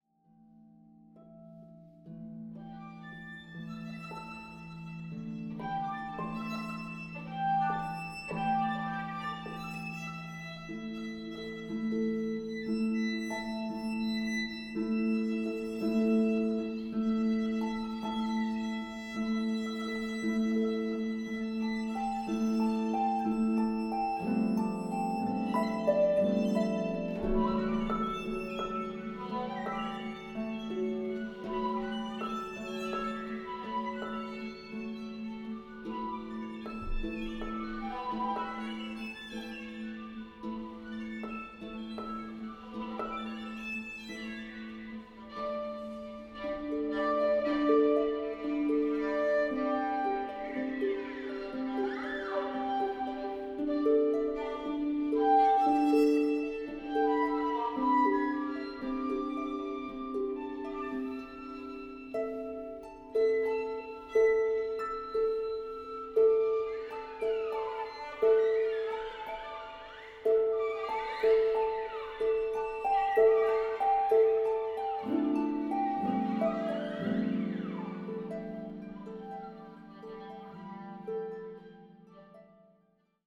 for Violin and Harp
Harp